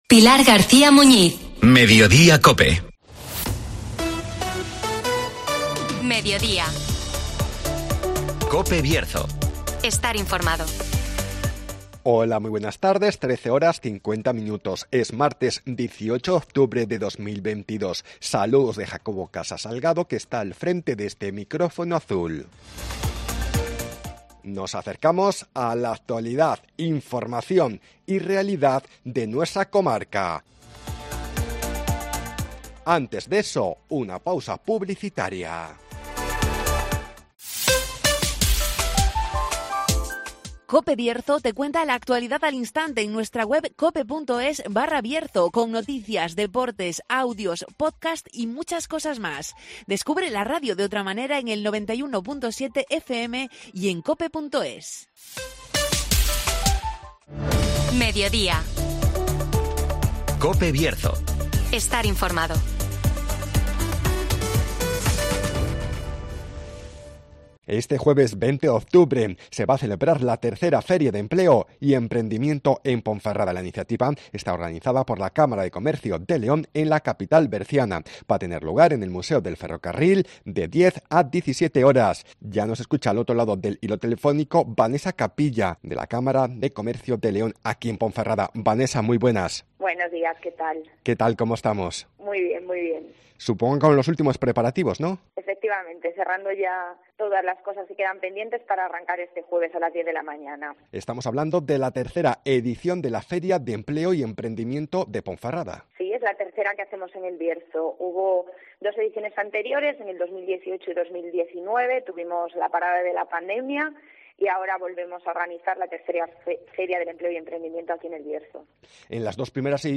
Este jueves, tercera Feria de Empleo y Emprendimiento en Ponferrada (Entrevista